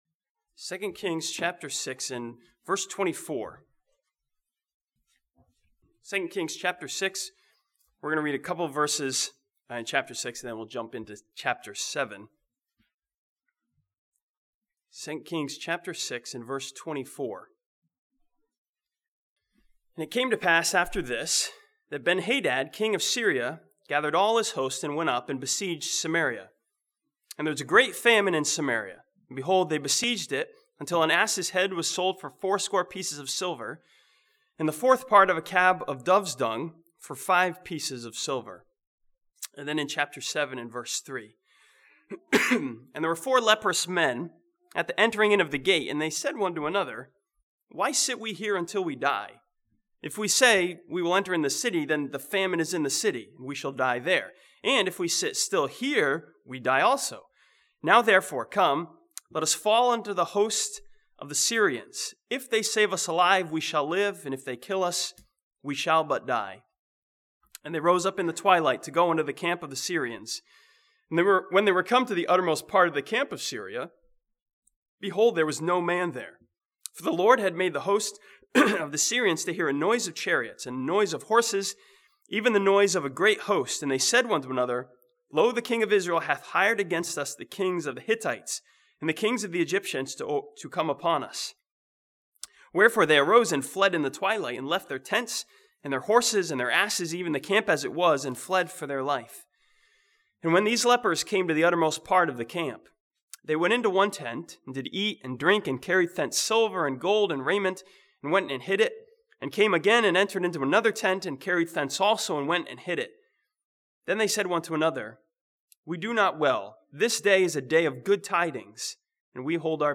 This sermon from 2 Kings chapter 7 learns from four men who went from famine to feasting as sees Jesus as our feast.